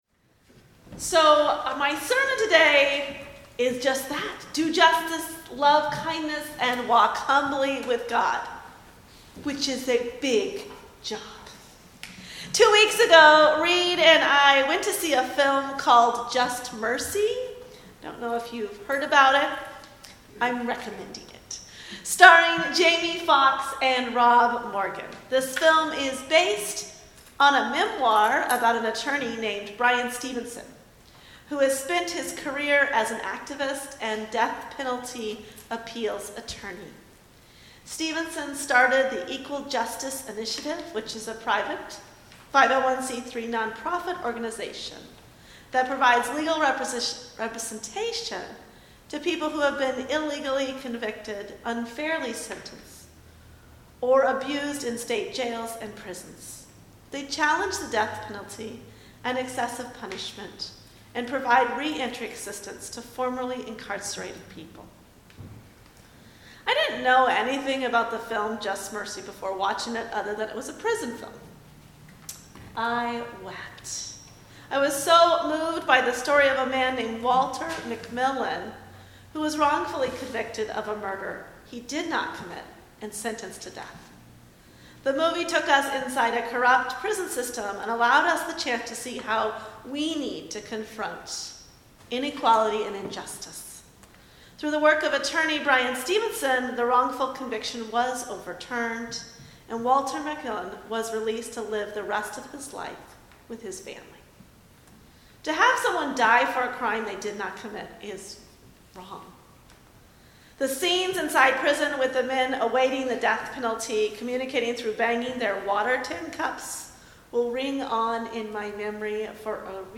Sermon_Sat_Feb_1_2020.mp3